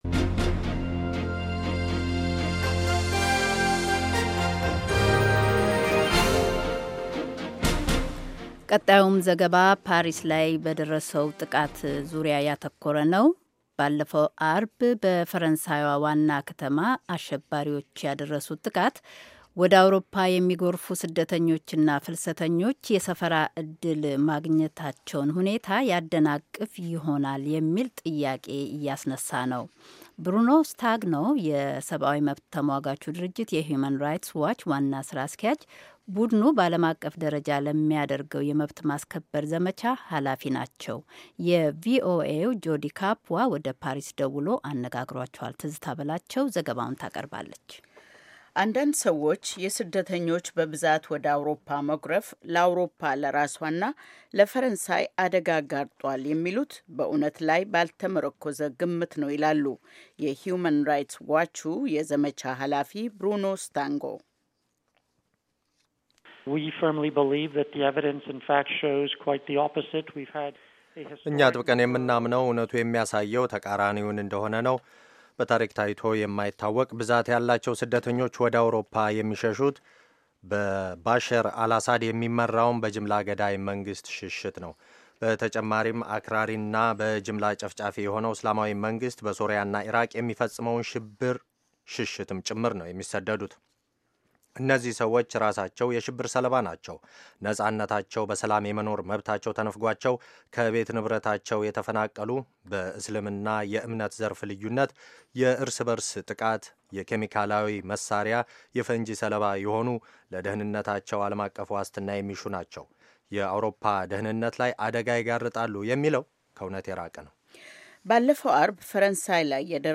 ከፓሪስ በስልክ ያደረጉትን ቃለ ምልልስ